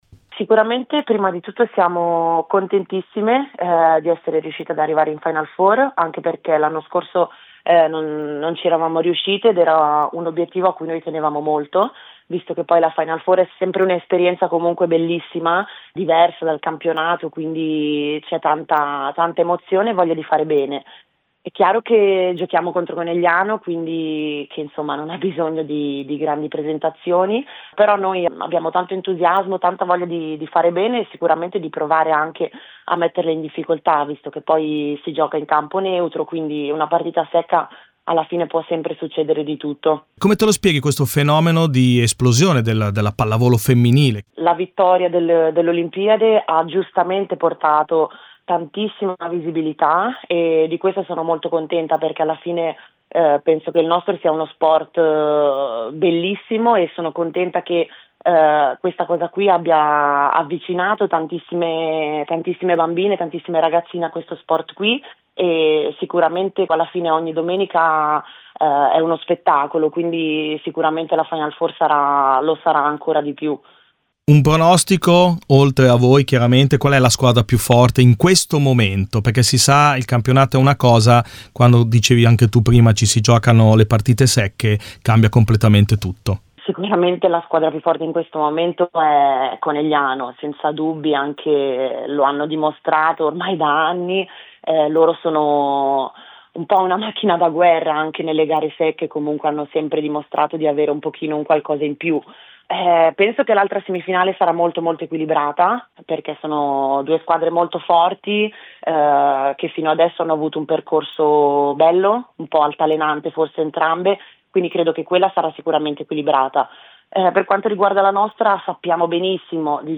Ai microfoni di Radio Bruno le voci di alcune delle protagoniste delle squadre che scenderanno in campo per la vittoria finale.